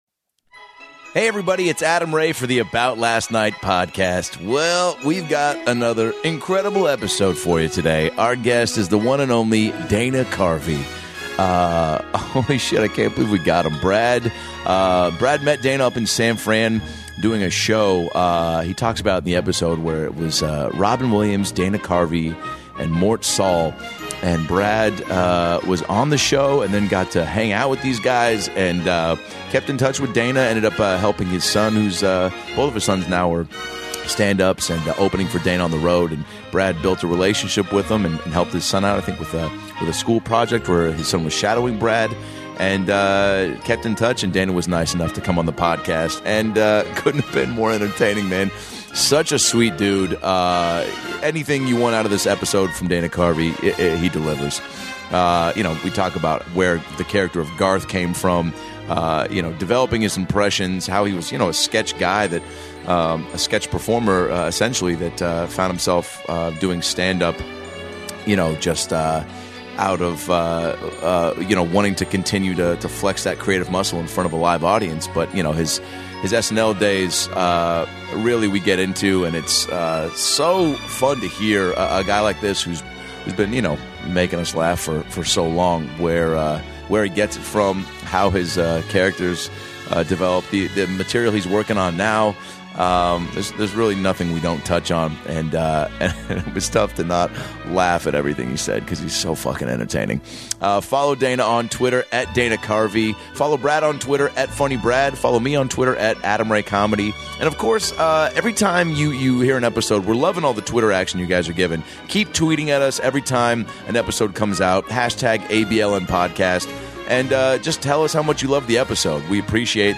From how he created Garth, to working with Phil Hartman, to visiting the White House, to meeting Chris Farley, his stories will blow your mind. It's 90 minutes of hilarity, complete with a visit from the Church Lady.